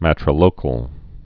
(mătrə-lōkəl)